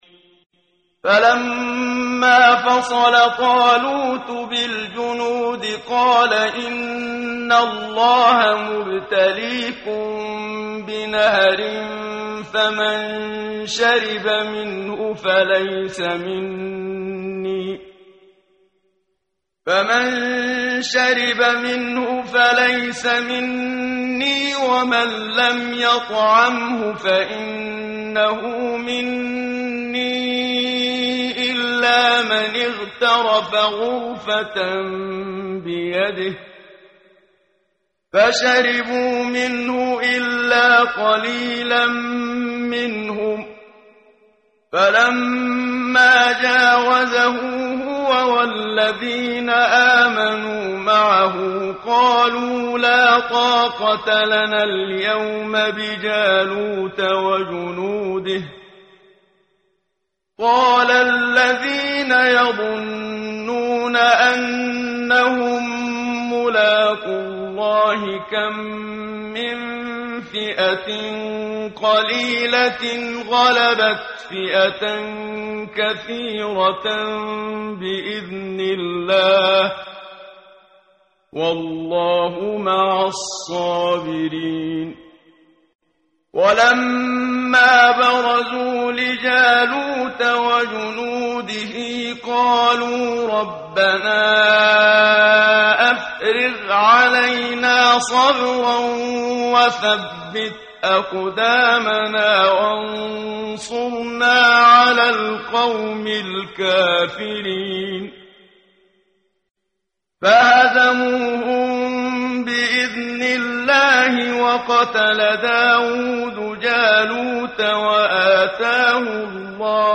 ترتیل صفحه 41 سوره مبارکه بقره (جزء دوم) از سری مجموعه صفحه ای از نور با صدای استاد محمد صدیق منشاوی